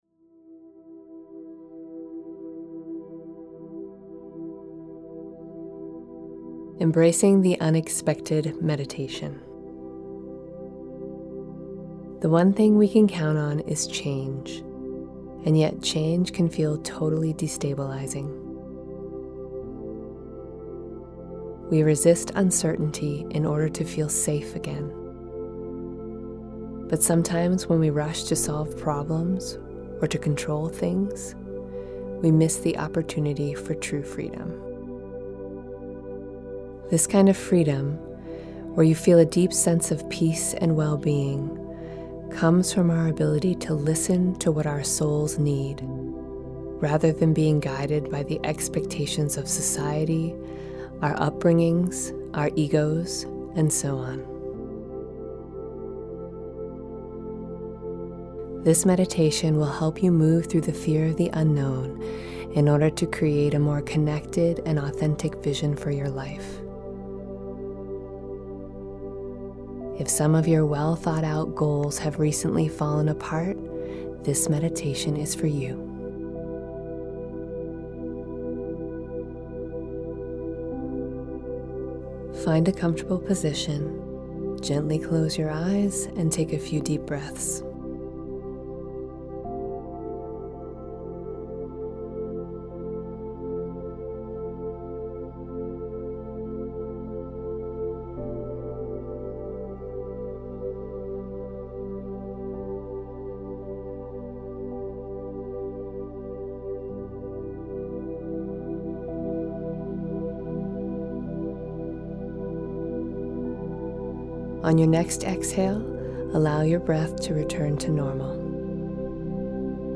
Embracing the Unexpected Meditation